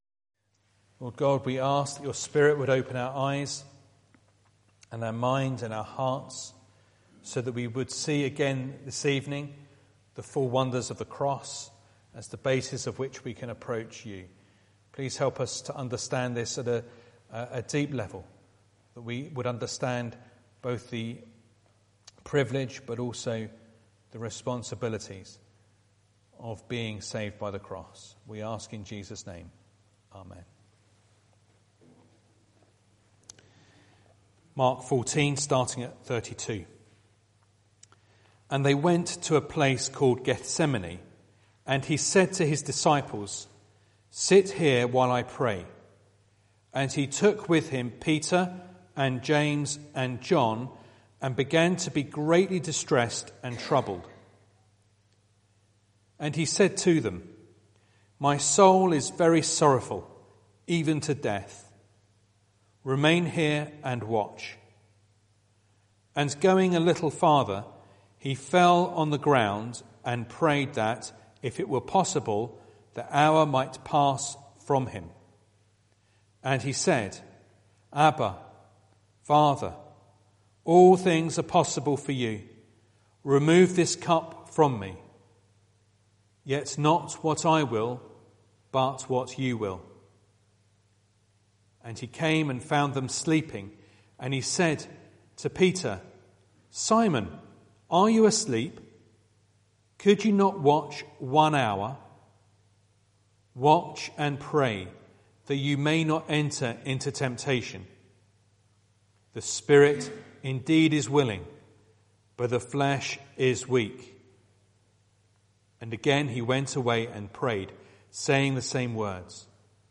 Service Type: Midweek